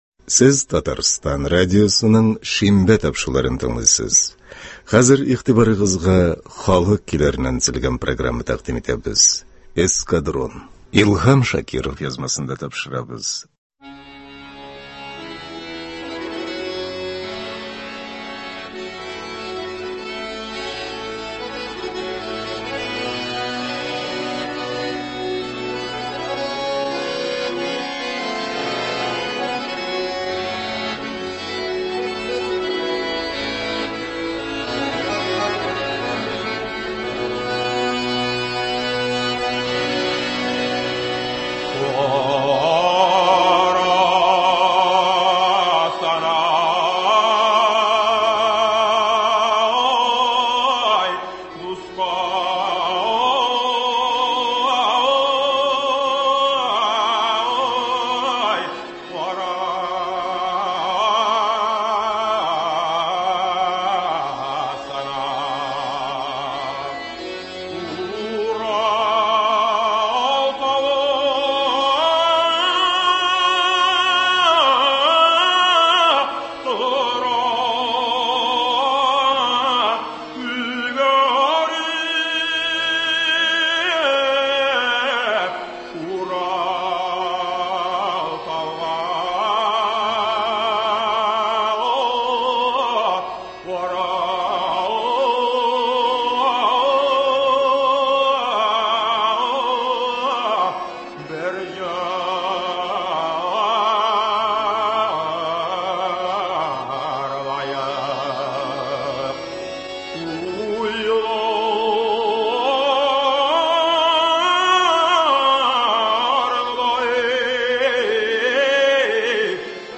Татар халык көйләре (12.11.22)
Бүген без сезнең игътибарга радио фондында сакланган җырлардан төзелгән концерт тыңларга тәкъдим итәбез.